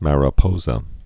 (mărə-pōzə, -sə)